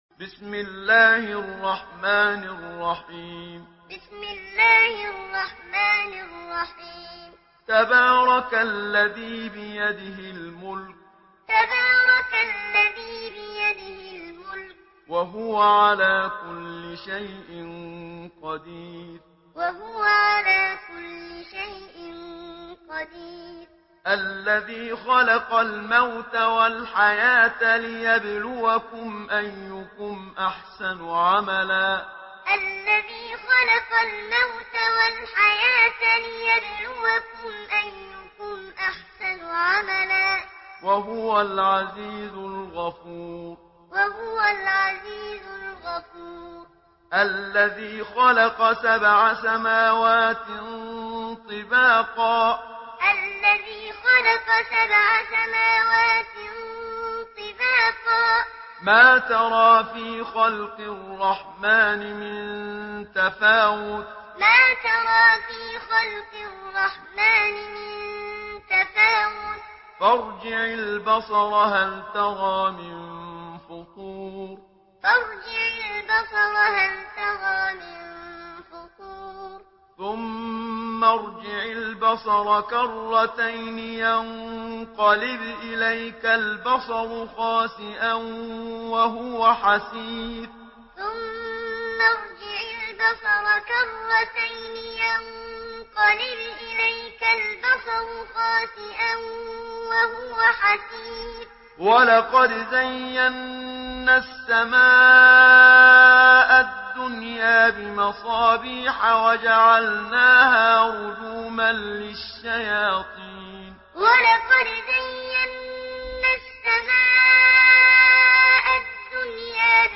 Surah Al-Mulk MP3 in the Voice of Muhammad Siddiq Minshawi Muallim in Hafs Narration
Listen and download the full recitation in MP3 format via direct and fast links in multiple qualities to your mobile phone.